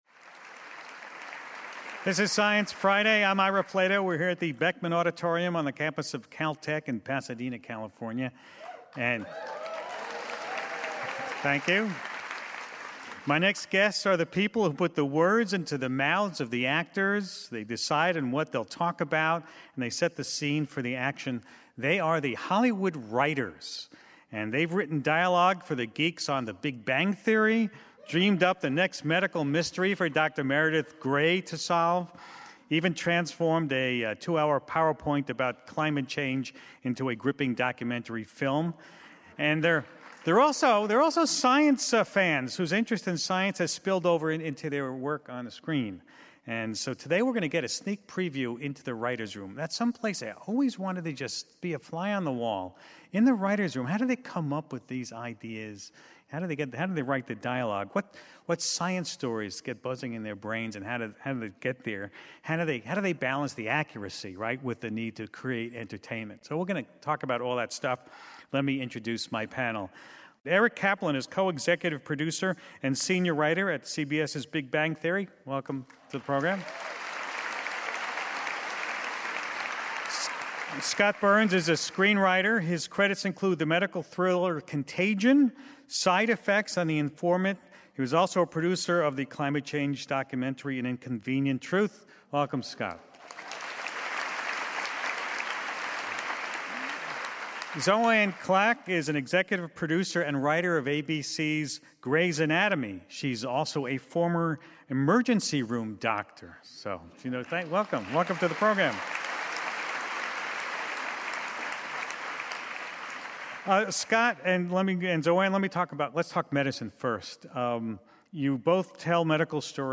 Hollywood T.V. and film writers explain how they balance scientific accuracy and storytelling.